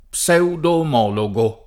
pseudomologo [ p S eudom 0 lo g o ]